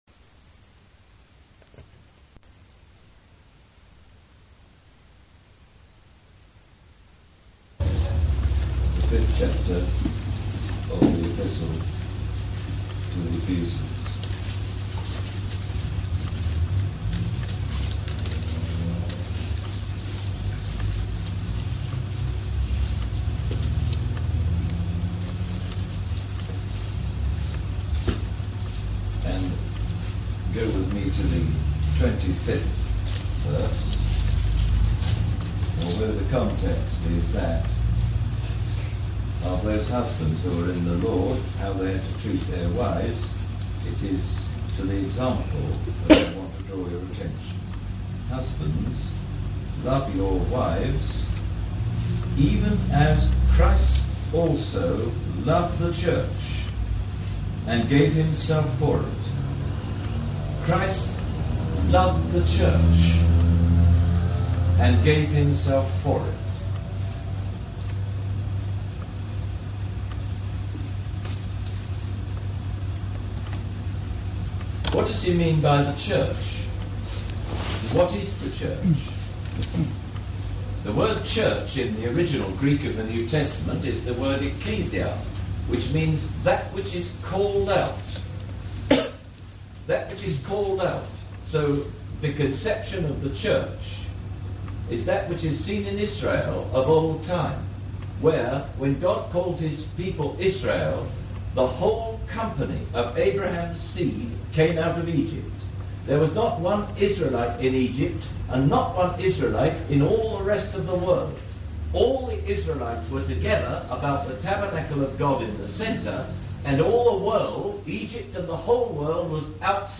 This is an audio sermon titled “The Church – What is it?”